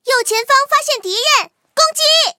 公羊2开火语音1.OGG